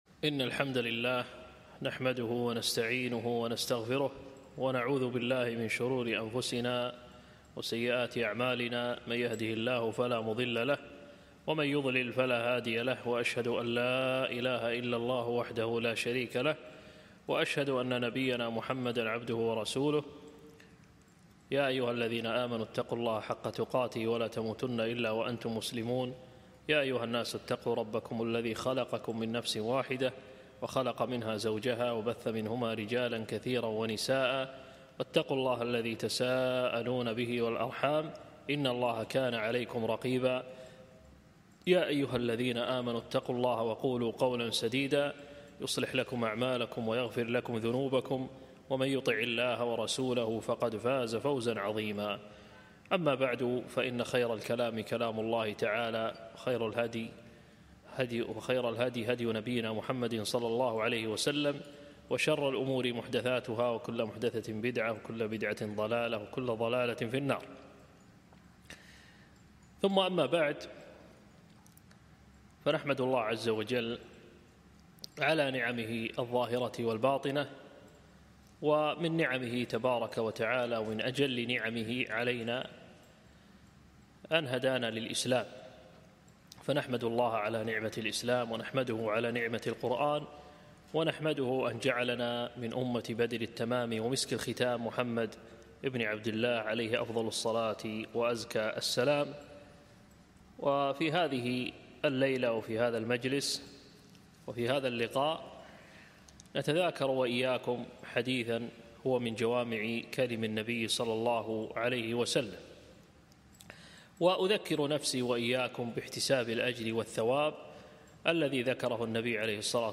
محاضرة - تأملات في الحديث ( نعمتان مغبون كثير من الناس )